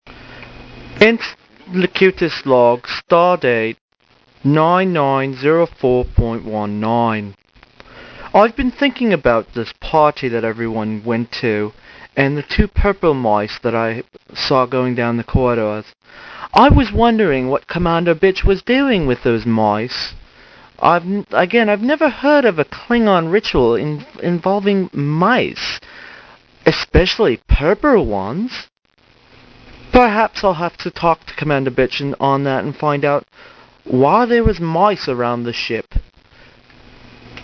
Here you will here some of the voice logs I did for the QOB.